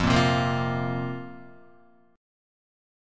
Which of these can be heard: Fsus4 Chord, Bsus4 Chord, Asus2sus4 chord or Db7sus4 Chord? Fsus4 Chord